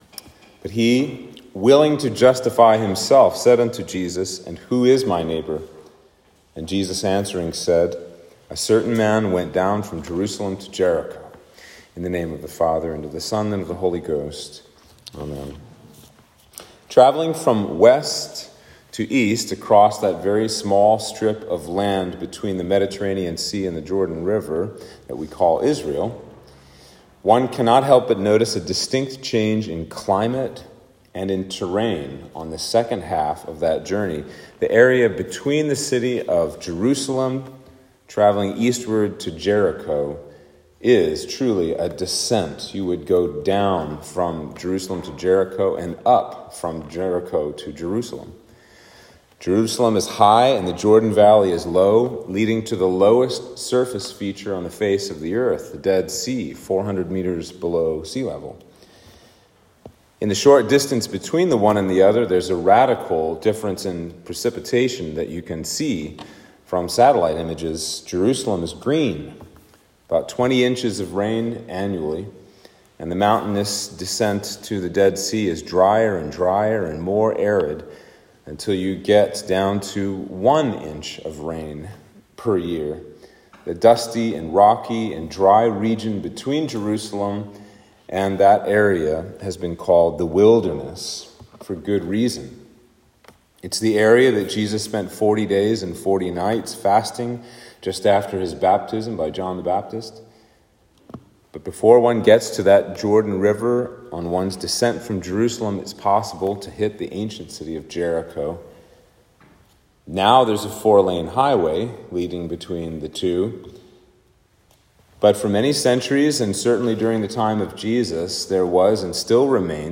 Sermon For Trinity 13